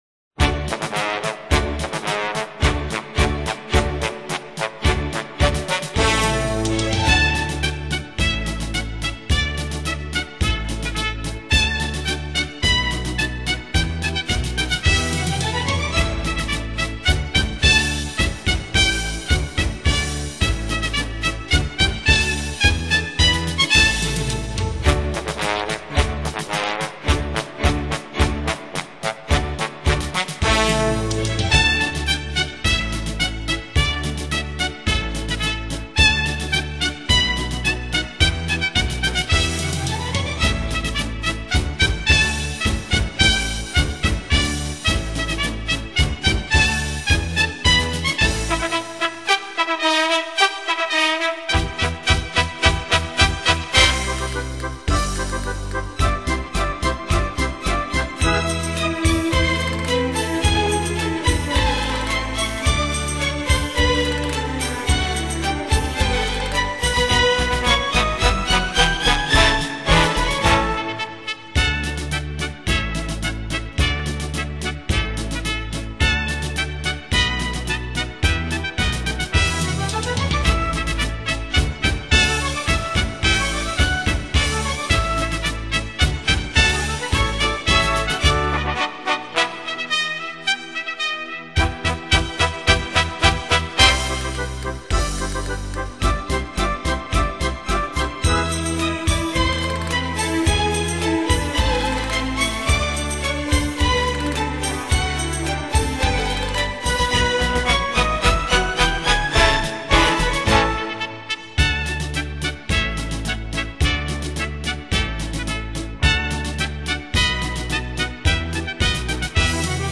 全新的小号带来典雅古朴饱含深情的传统名曲。